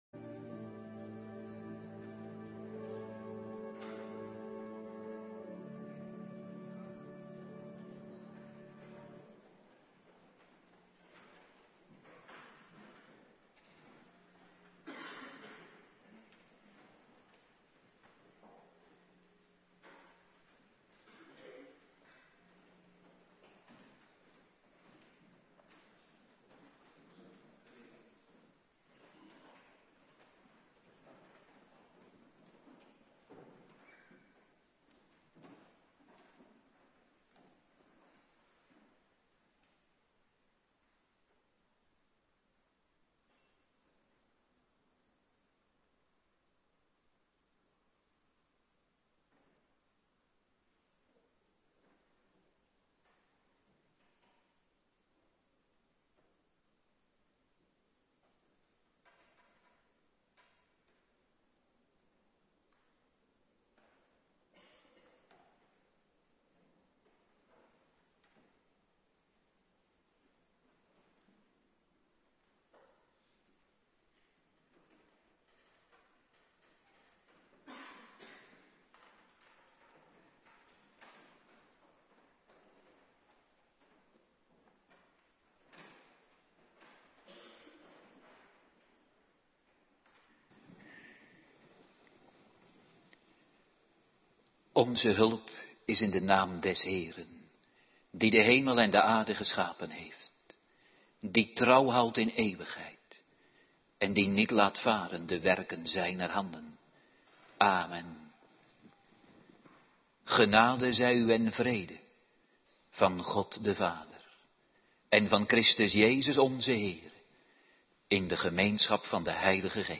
Woensdagavonddienst
17-29 Terugluisteren Bijbellezing Rom. 2:17-29